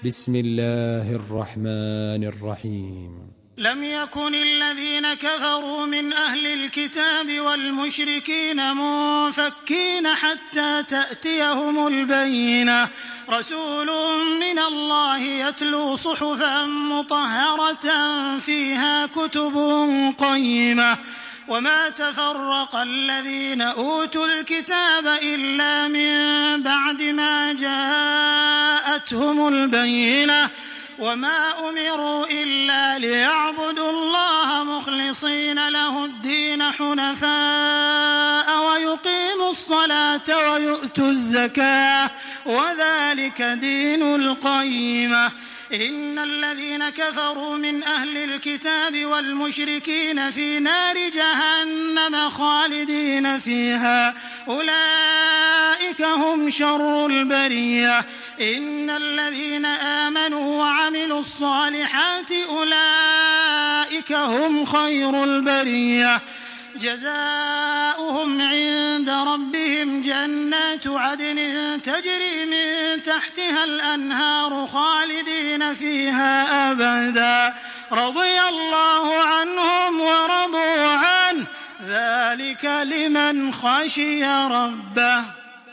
Surah আল-বায়্যিনাহ্ MP3 by Makkah Taraweeh 1426 in Hafs An Asim narration.
Murattal